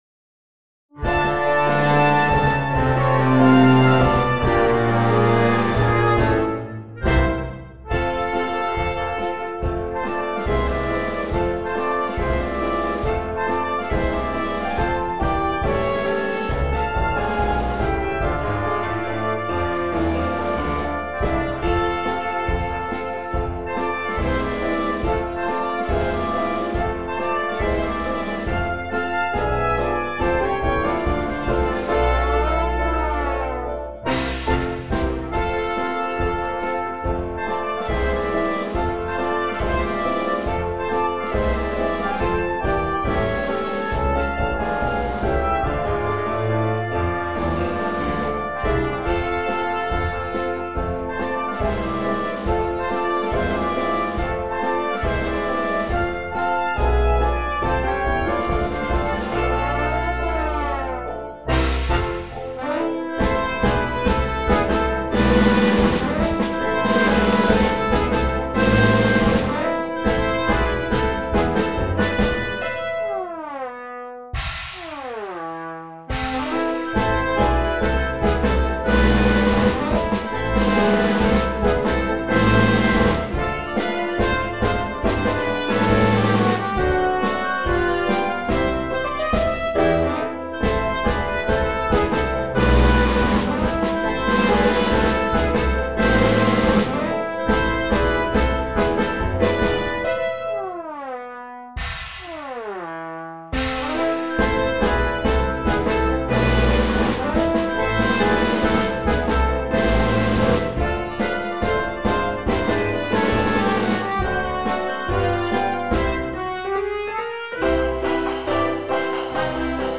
Early jazz orchestra